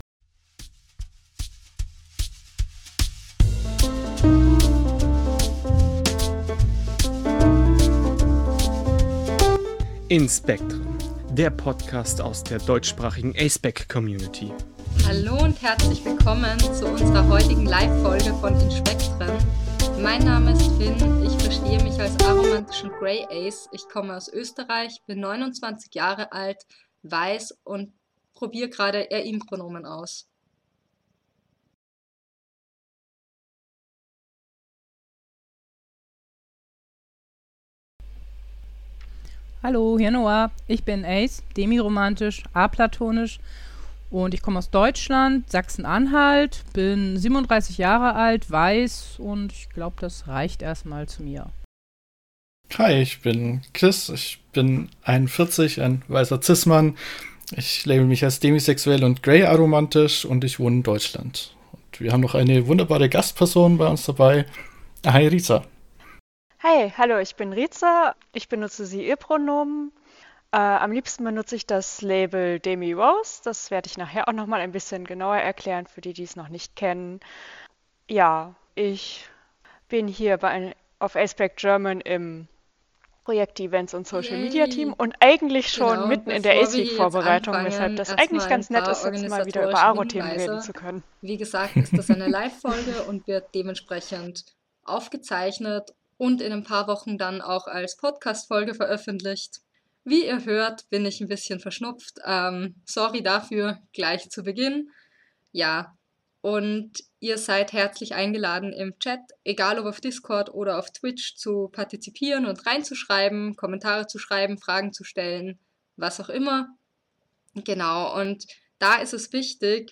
86 – Aro*spec in der A*spec-Community (Mitschnitt Livefolge zum TAS 2025) – InSpektren